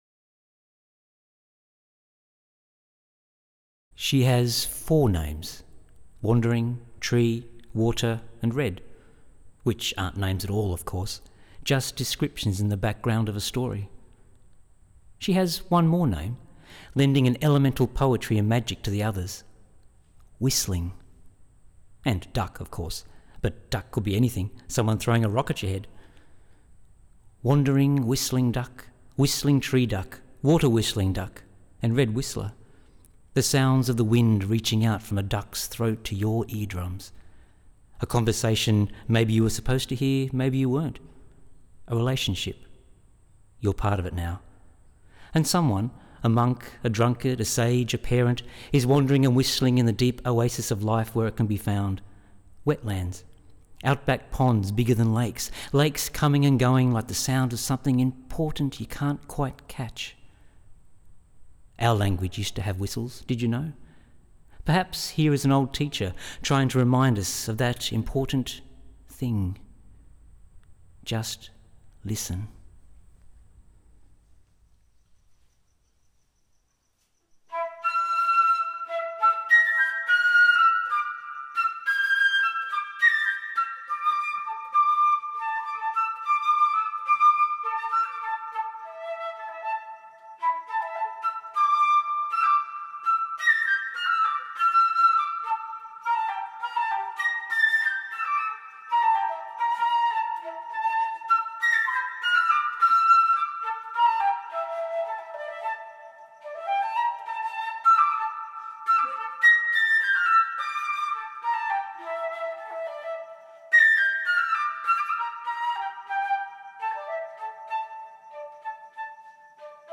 Bimblebox 153 Birds is a unique installation of artist prints, poetry, prose and musician’s birdcalls.
flute in C.